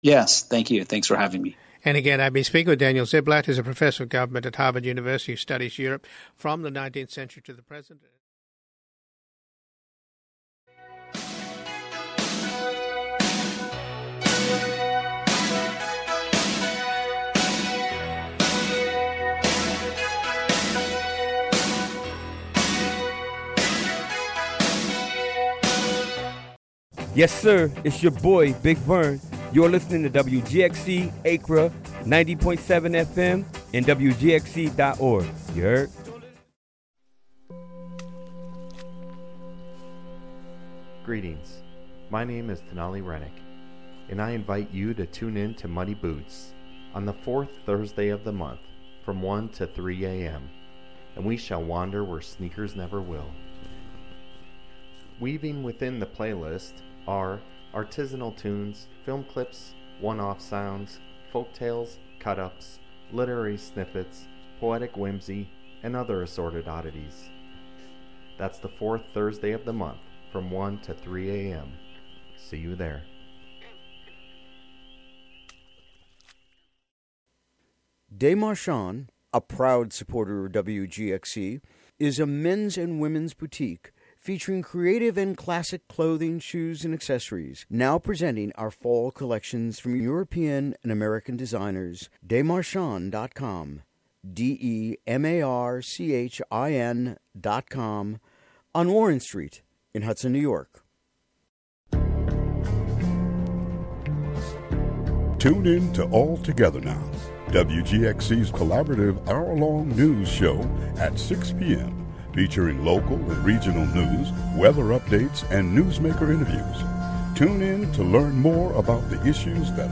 The Hudson Common Council Police Committee meeting from June 25 is excerpted here. "All Together Now!" is a daily news show brought to you by WGXC-FM in Greene and Columbia counties.